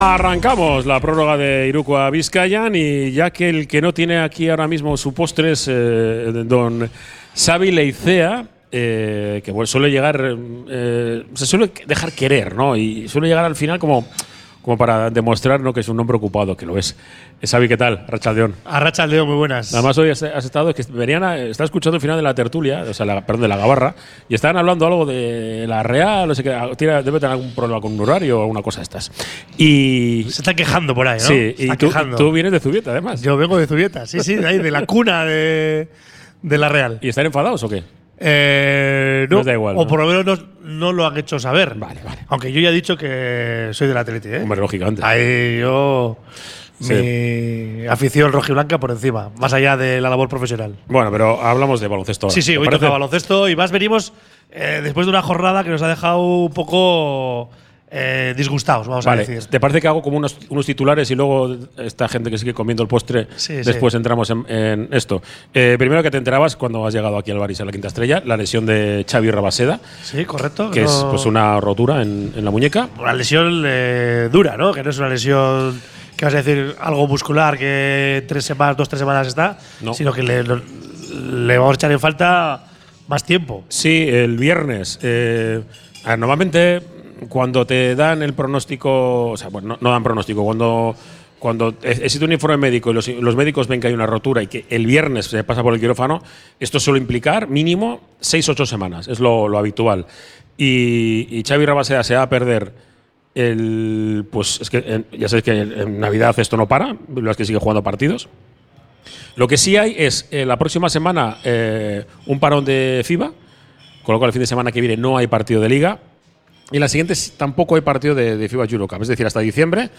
Desde el Bar Izar la Quinta Estrella de Santutxu